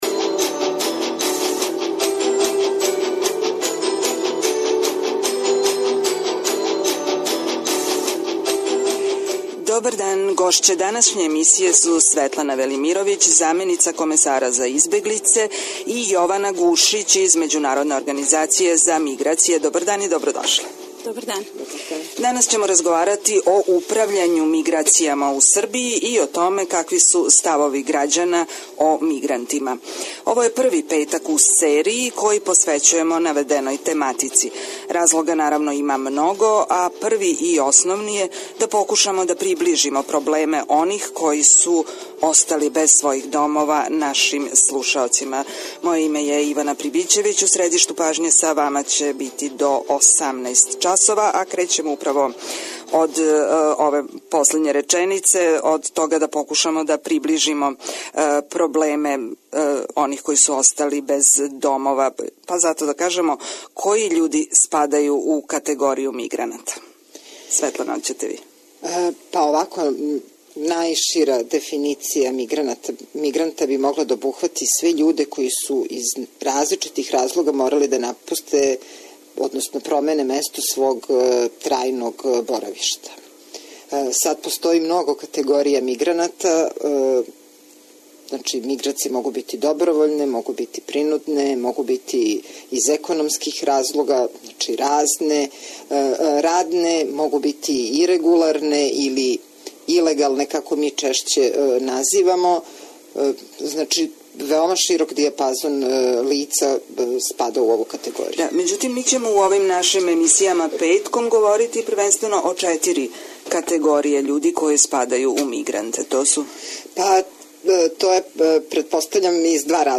Управо зато, Радио Београд 1, у сарадњи са Комесаријатом за избеглице и Међународном организацијом за миграције, емитује серију разговора о овој тематици.